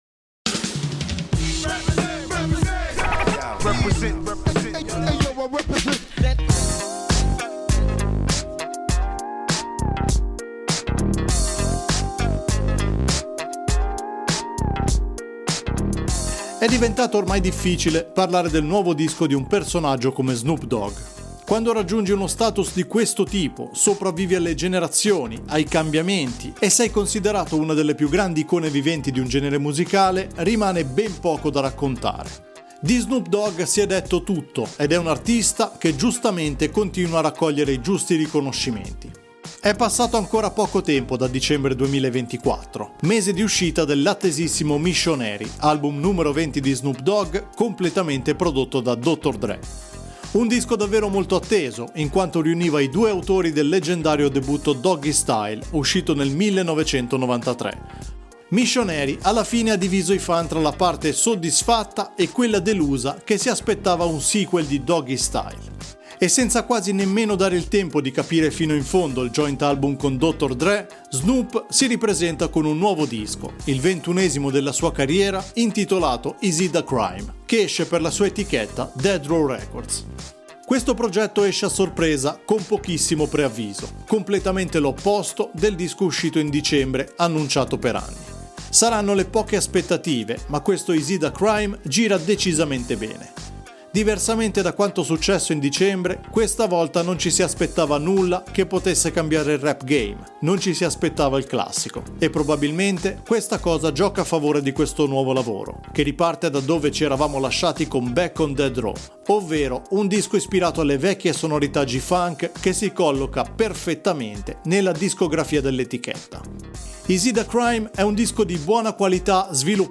in pieno stile G-Funk